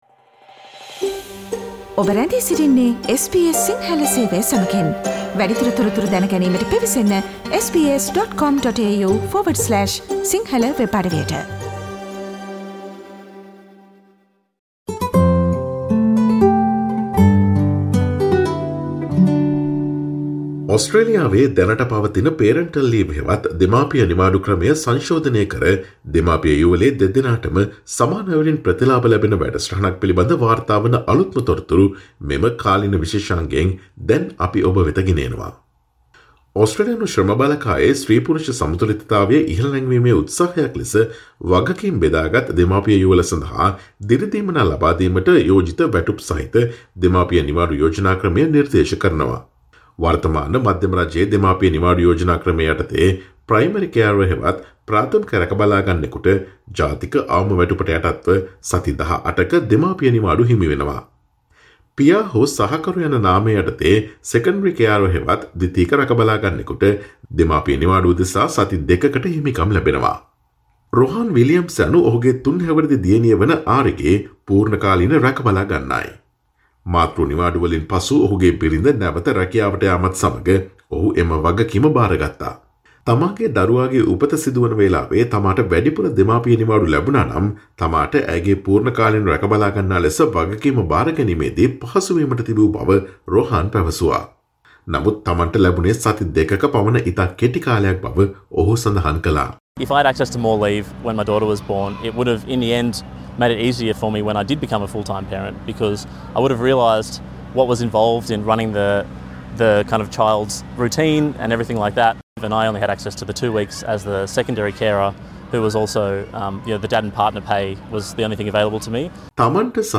ඔස්ට්‍රේලියාවේ දැනට පවතින parental leave ක්‍රමය සංශෝධනය කර දෙමාපිය යුවලේ දෙදෙනාටම සමාන අයුරින් ප්‍රතිලාභ ලැබෙන වැඩසටහනක් පිළිබඳ වාර්තා වන අලුත්ම තොරතුරු රැගත් අප්‍රේල් 09 වන දා සිකුරාදා ප්‍රචාරය වූ SBS සිංහල ගුවන් විදුලියේකාලීන තොරතුරු විශේෂාංගයට සවන්දෙන්න.